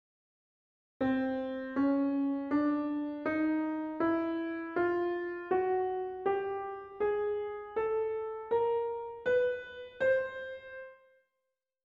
Well, an ordinary (diatonic) scale — as in Ex. 2 below (of C major) — uses only 7 of the available 12 notes between one octave and the next, whereas the chromatic scale gets to use all twelve of them (Ex. 1).
Chromatic diatonic
chromatic.mp3